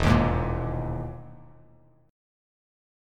EM#11 chord